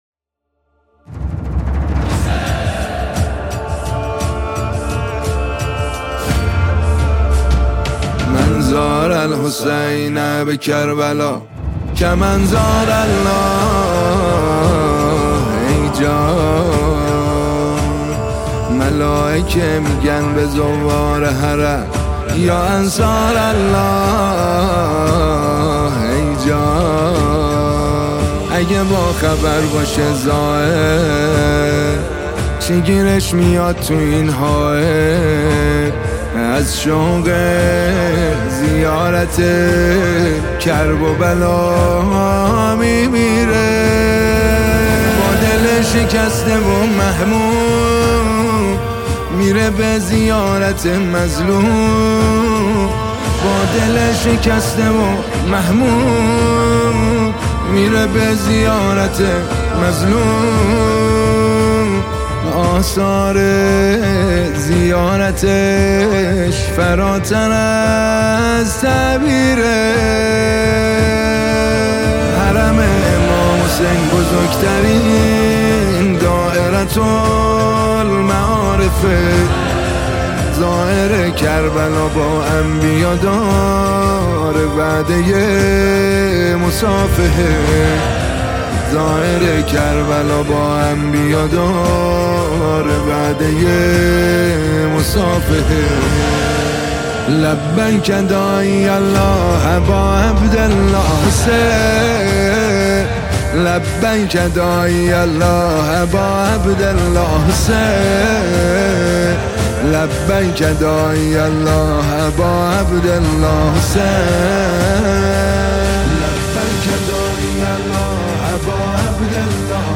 نماهنگ و مناجات دلنشین